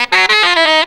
JAZZY C.wav